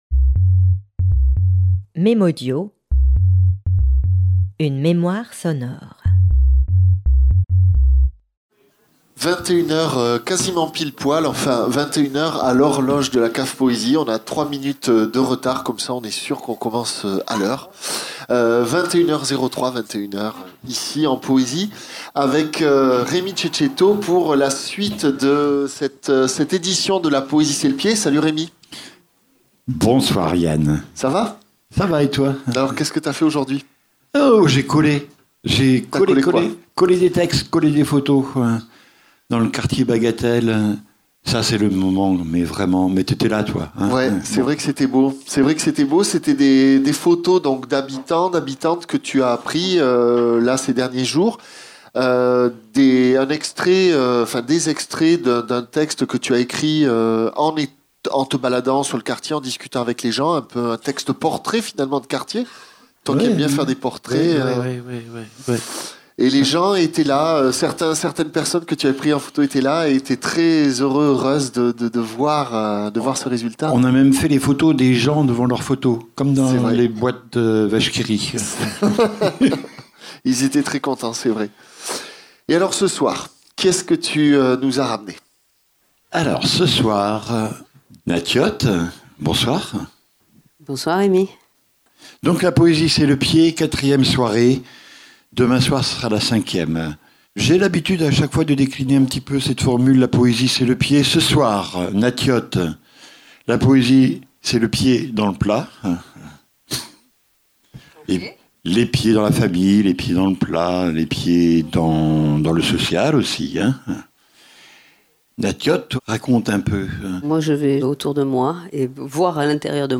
Poésie